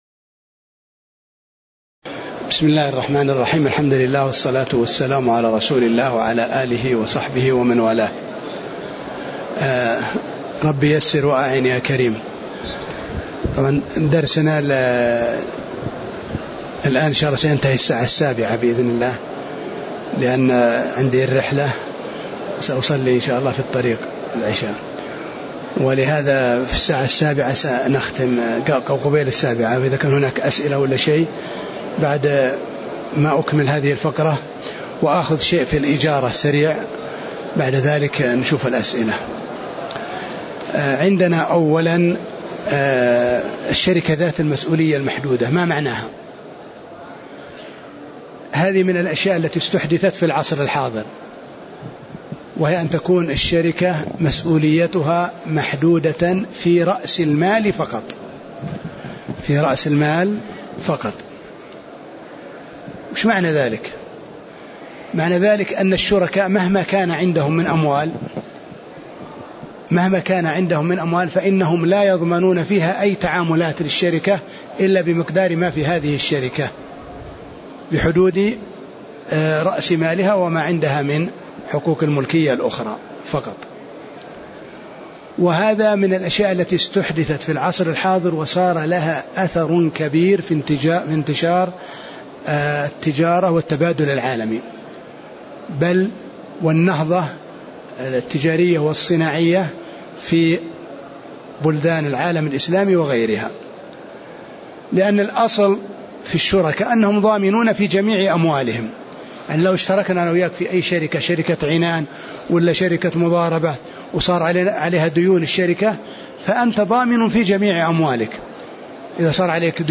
تاريخ النشر ٢ ربيع الثاني ١٤٣٨ هـ المكان: المسجد النبوي الشيخ